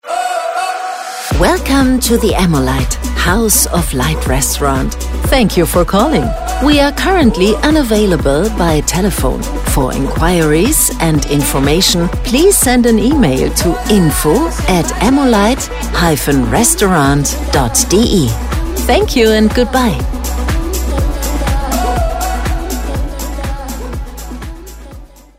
Telefonansagen mit echten Stimmen – keine KI !!!
Um so mehr, freuen wir uns, dass wir gerade die neuen Telefonansagen in 3 Sprachen für die neue Telefonanlage produzieren durften.